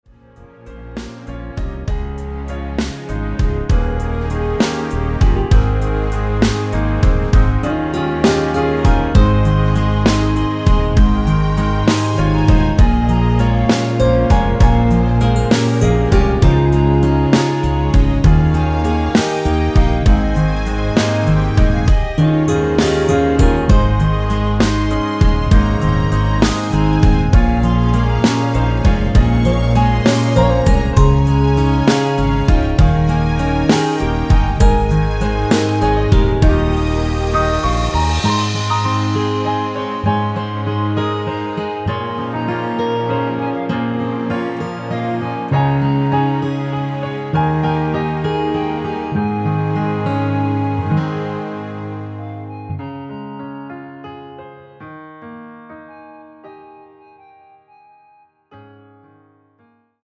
고음 부분의 부담없이 부르실수 있게 편곡 하였습니다.
원키(Easy Ver.) 멜로디 포함된 MR 입니다.
Ab
앞부분30초, 뒷부분30초씩 편집해서 올려 드리고 있습니다.
중간에 음이 끈어지고 다시 나오는 이유는